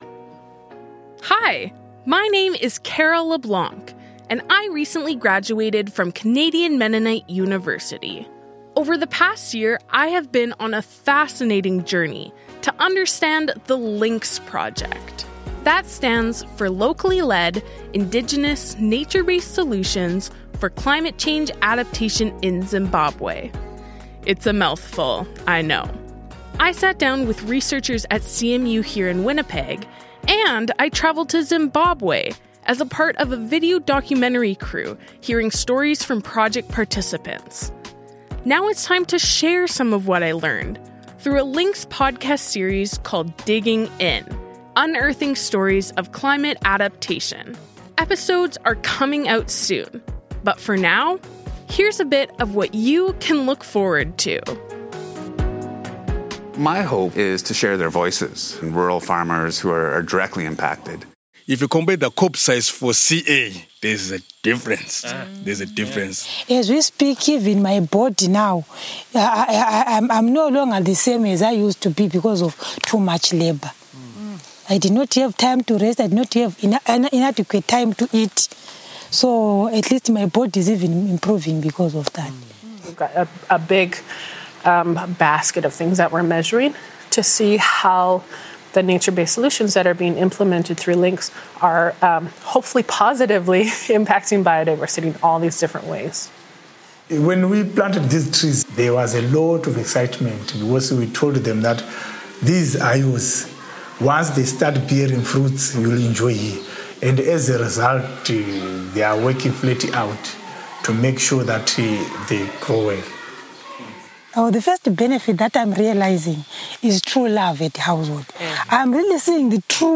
interviews different CMU researchers